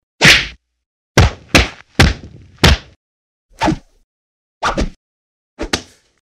Удары в карате из кино